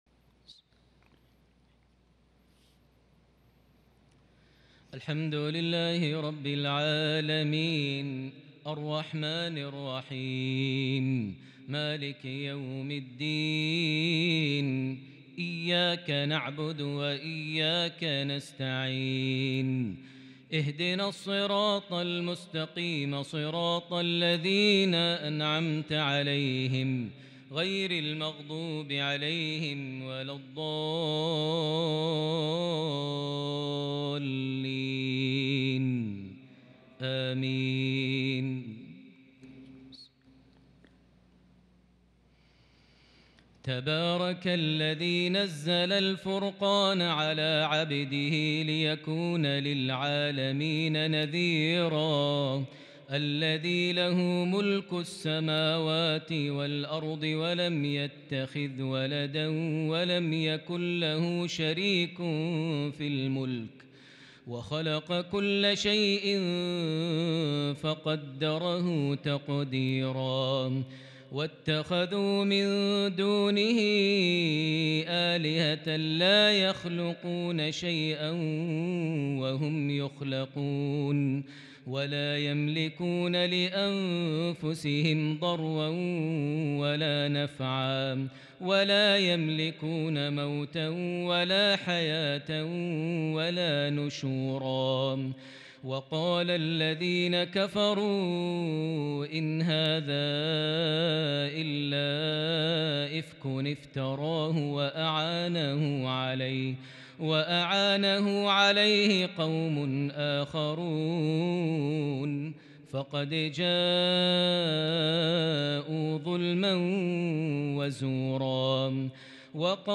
lsha 5-9-2021 prayer from Surah Al-Furqan 1-16 > 1443 H > Prayers - Maher Almuaiqly Recitations